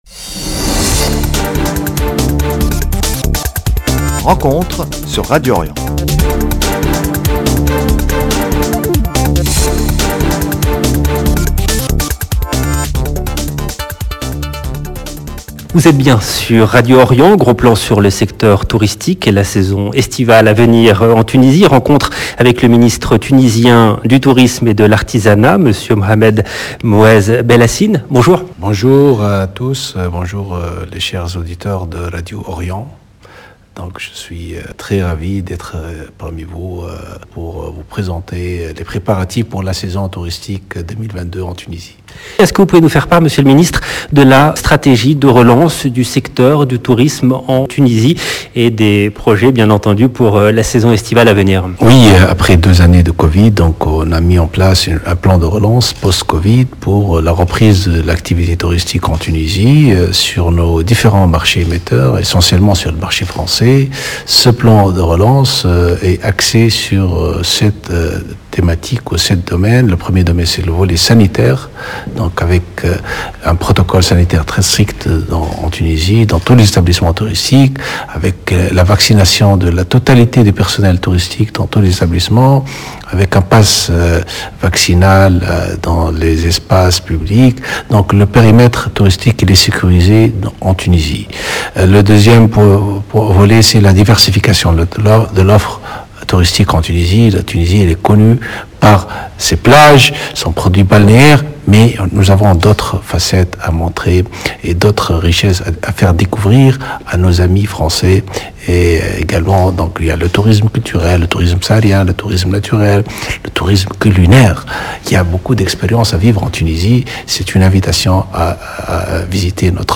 Tunisie 19 avril 2022 - 11 min 57 sec Mohamed Moez Belhassine, ministre tunisien du Tourisme et de l’Artisanat LB Rencontre, samedi 16 avril 2022 Rencontre avec le Ministre tunisien du Tourisme et de l’Artisanat, M. Mohamed Moez Belhassine. Gros plan sur la stratégie du gouvernement tunisien pour relancer le secteur touristique.
Le ministre tunisien qui assure que l’année 2022 sera celle de la reprise. Entretien.